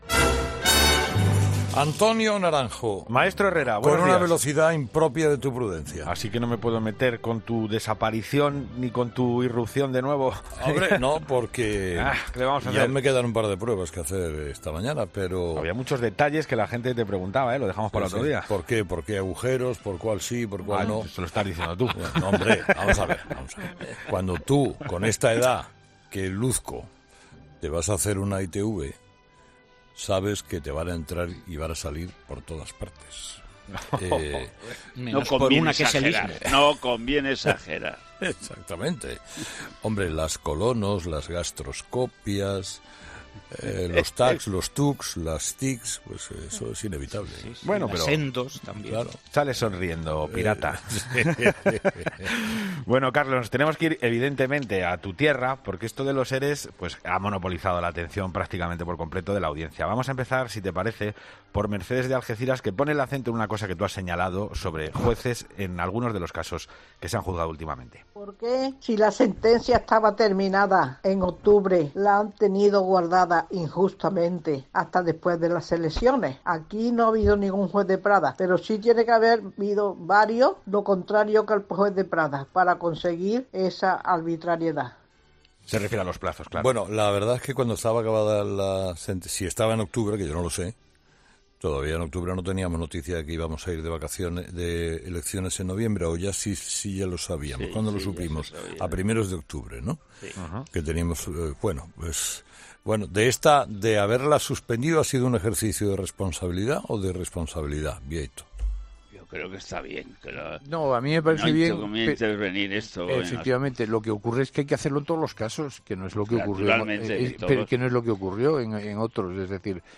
Los oyentes, de nuevo, protagonistas en 'Herrera en Cope' con su particular tertulia.
Avalancha de mensajes en el contestador de ‘Herrera en Cope’ con el tema del día: la sentencia de los ERE.